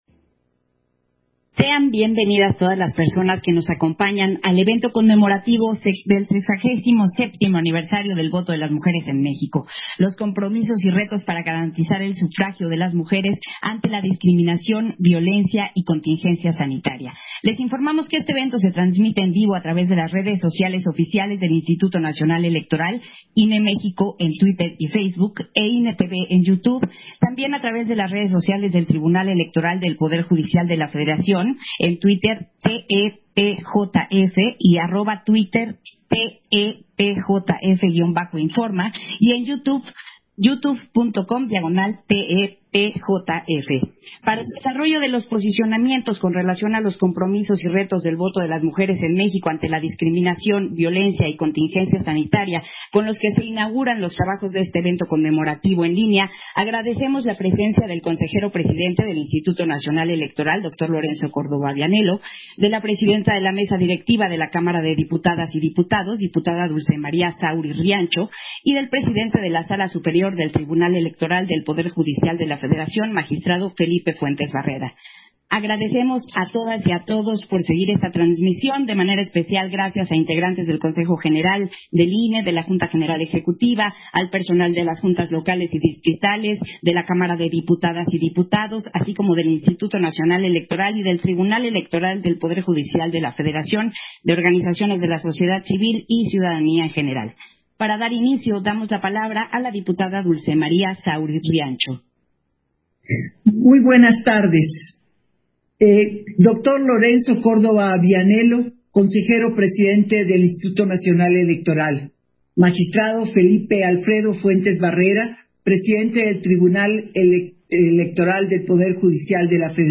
Versión estenográfica del posicionamiento con relación del voto de las mujeres en México, del evento conmemorativo de su 67º aniversario